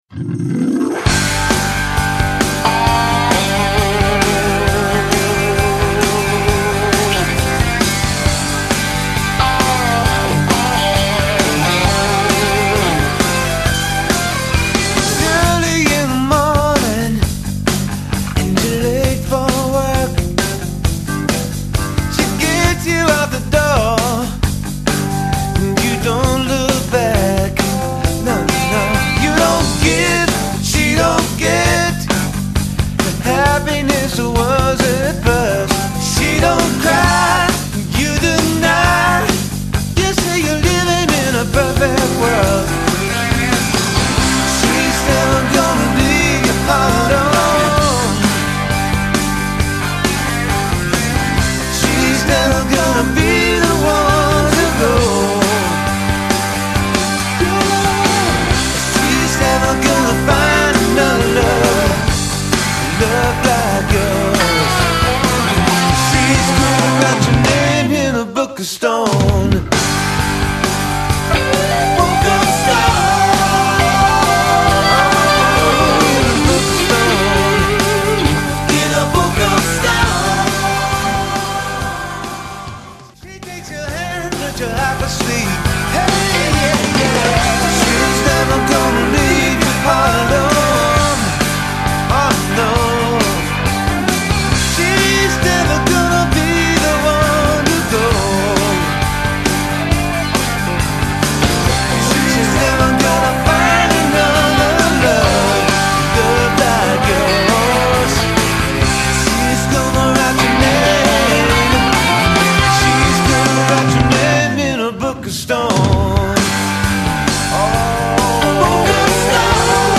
classic / blues rock